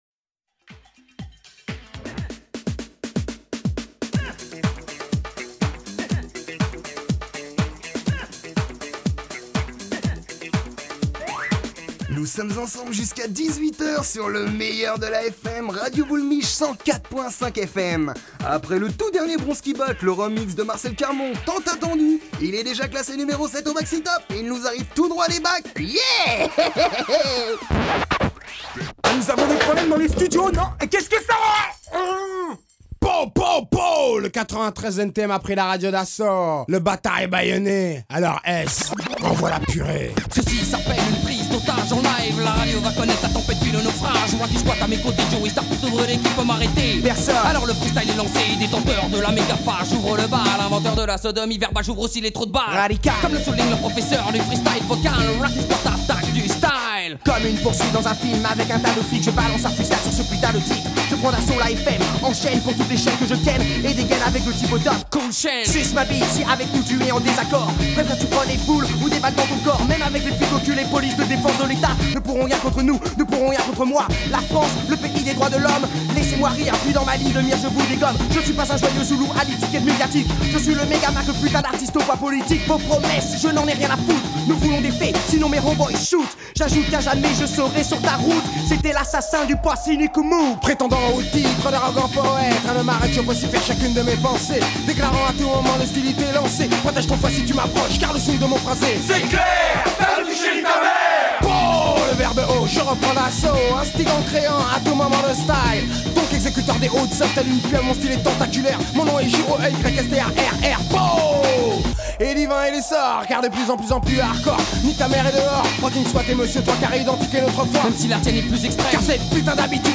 Jour 3 - Demi Festival / ITW & Micro-Trottoirs